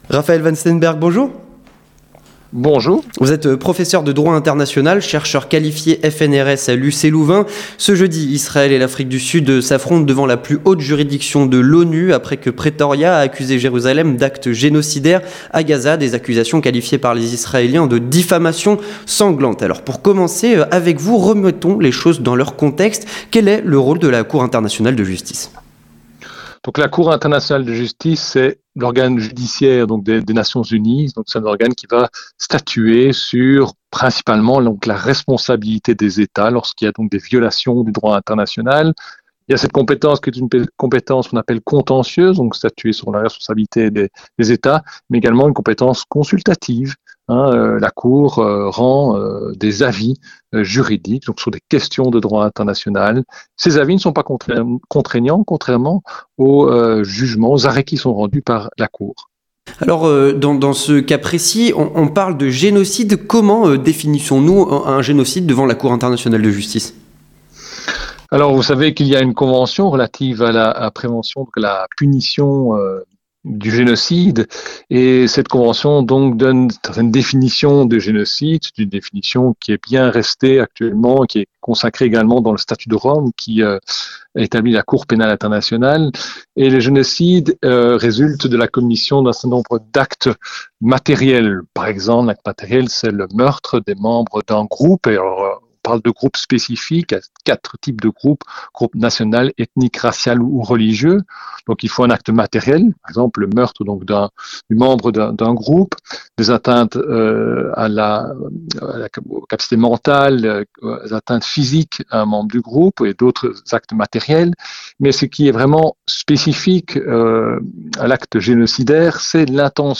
3. Les Chroniques de la Matinale